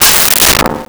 Metal Strike 04
Metal Strike 04.wav